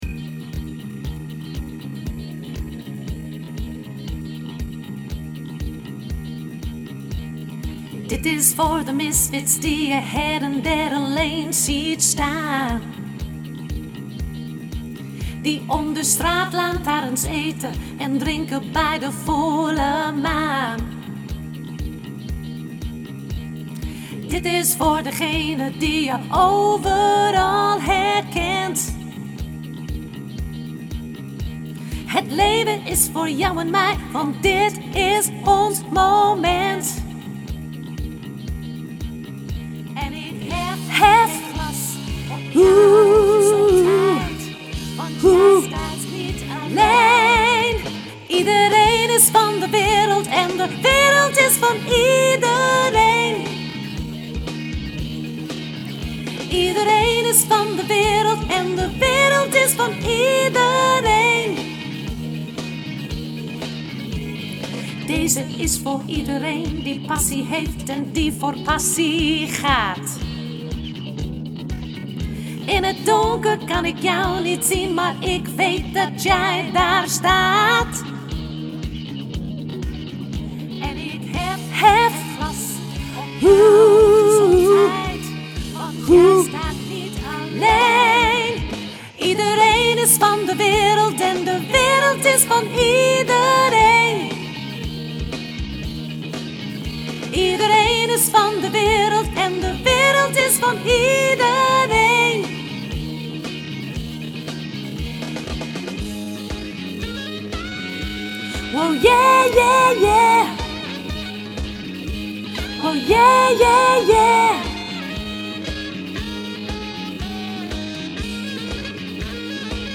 sopraan mezzo